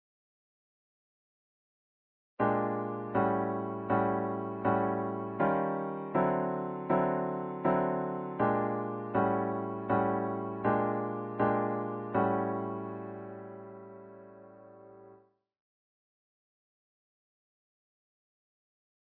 In the blues.
In this next idea, we use the half step motion from above to set up the One and (fast four) Four chords. The notation drives the quarter note rhythms on the beat. Click the pic for a live rendition of the following magic.